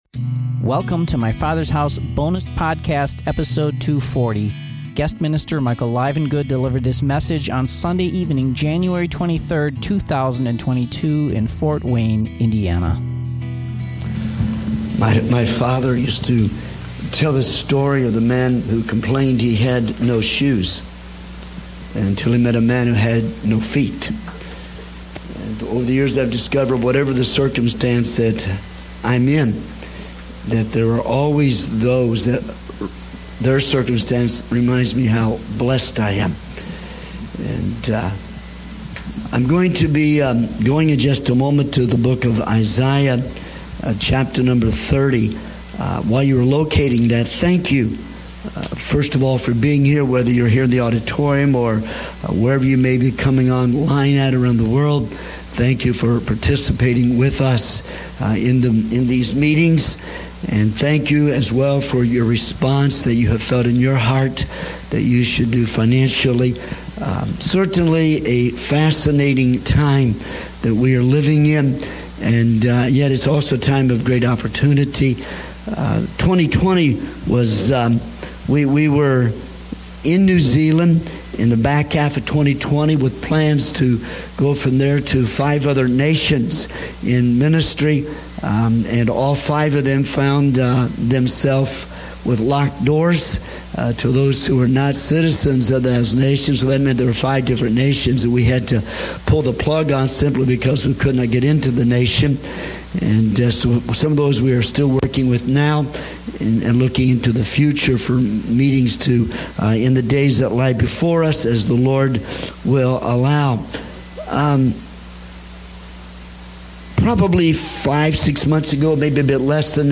January 23 Evening Service